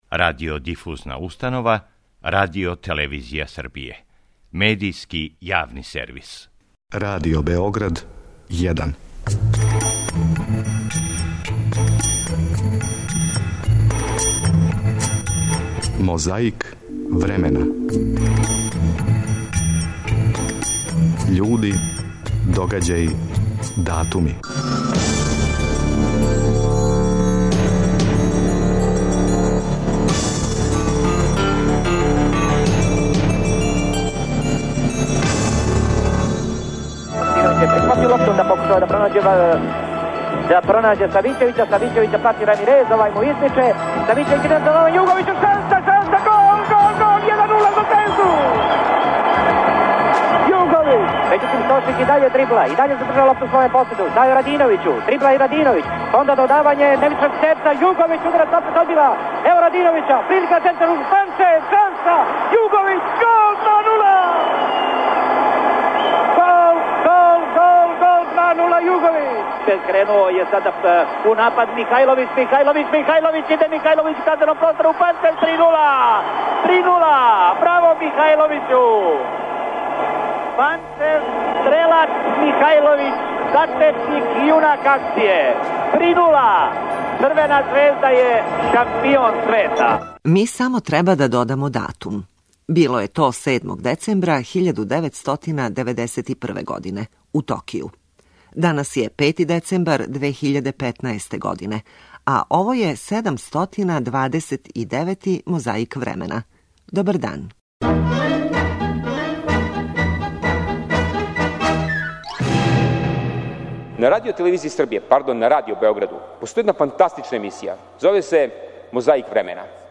Подсећамо и како је 7. децембра 1998. године говорио председник републичке владе Мирко Маријановић.